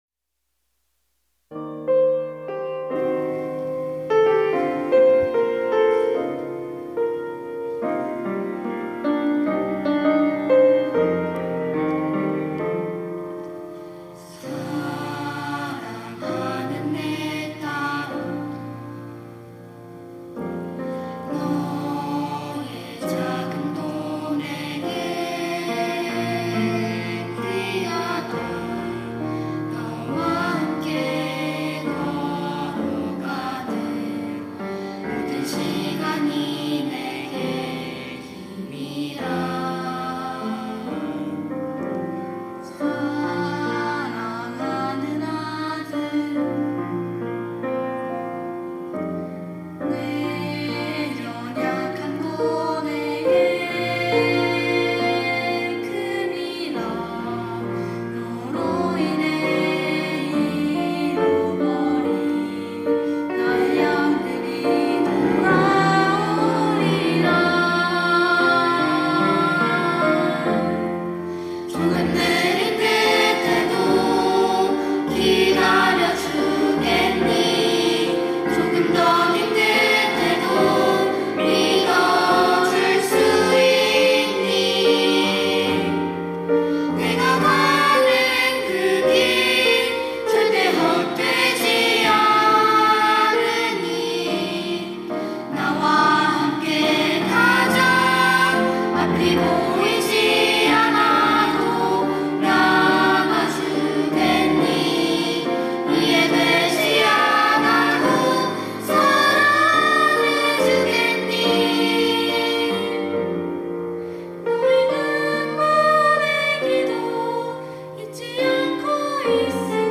특송과 특주 - 하나님의 열심
유스콰이어